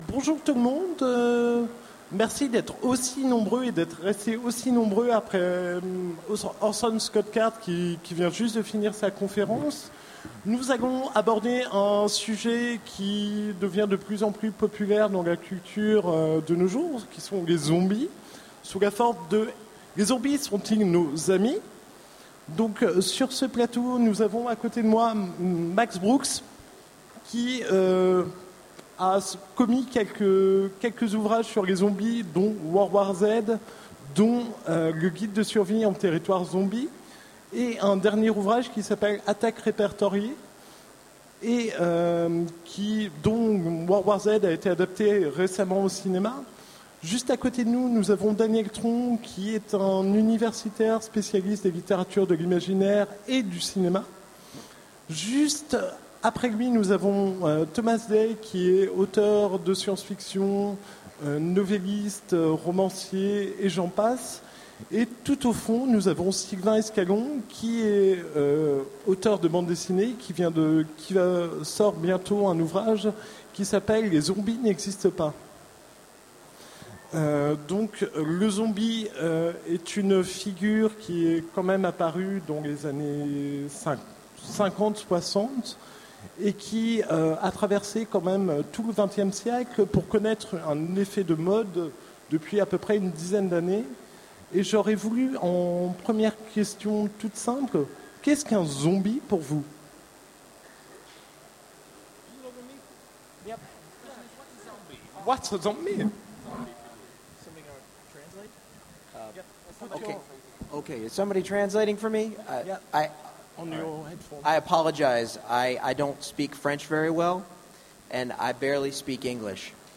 Utopiales 13 : Conférence Les zombies sont-ils nos meilleurs amis ?